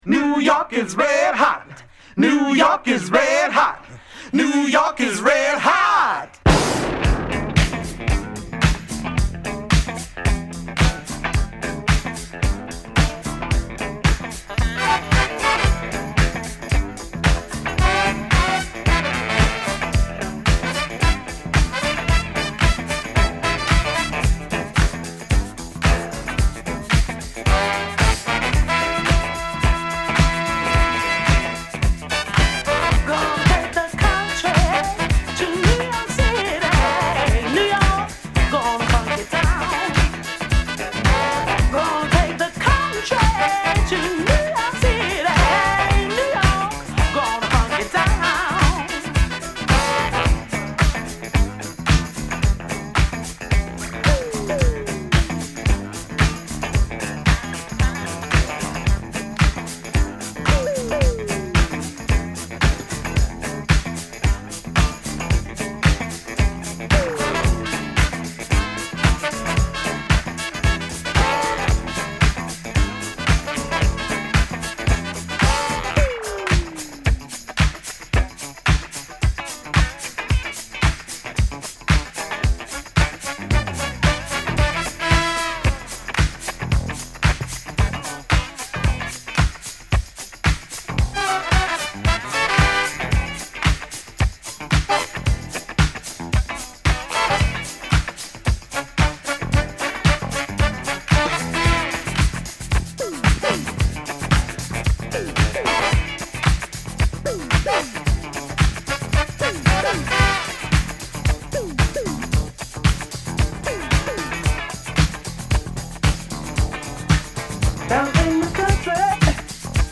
タイトなドラムに、JB's辺りを思わすファンキーなカッティングギター、ホーンが絡む骨太ディスコファンクチューン！